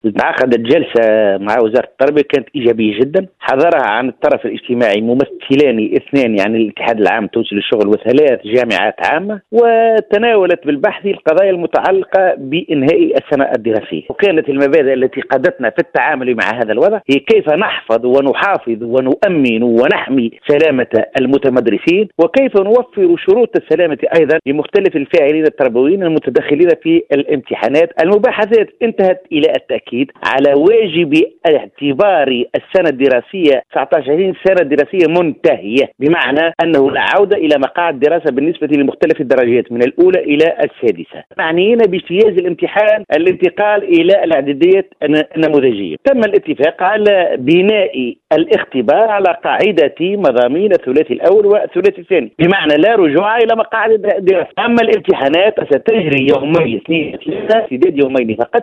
في تصريح لموزاييك اف ام